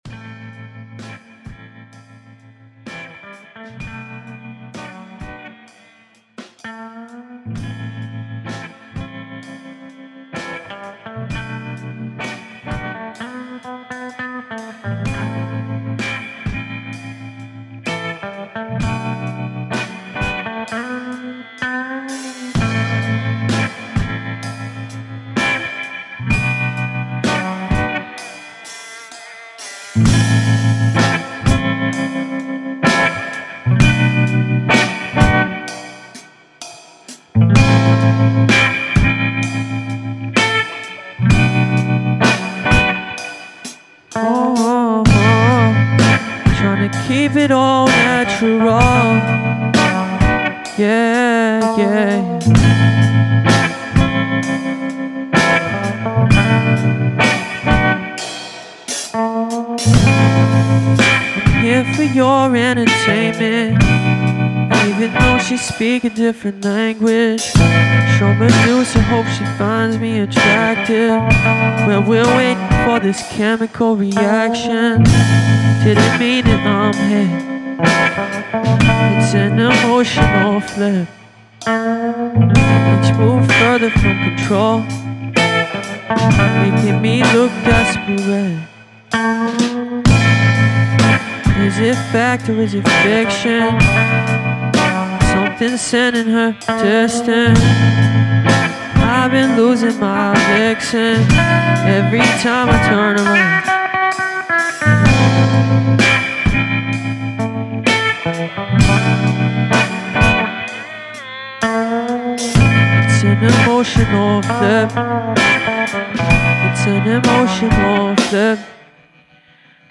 Additional Guitar & Vocals